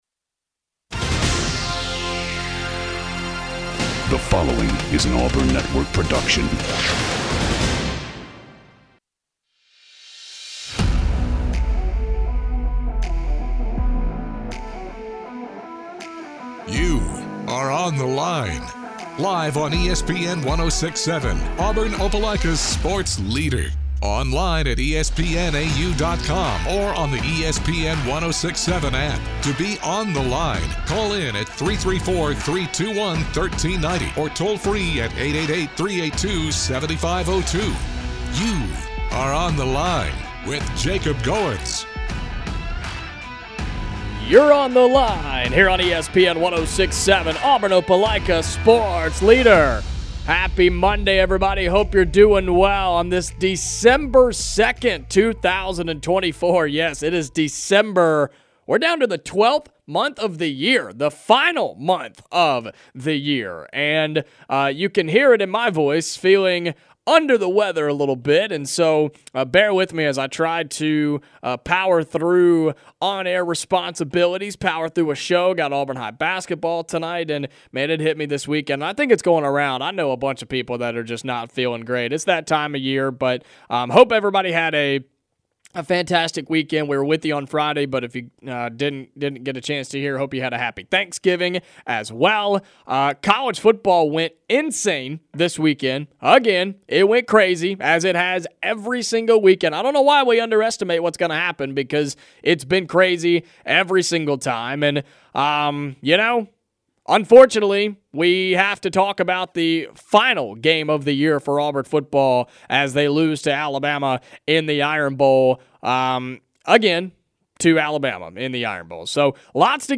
The guys go through the biggest results in college football from over the weekend and contemplate the upcoming college football playoff rankings. Callers and texters give their insights on the game and if Hugh Freeze should be the coach moving forward.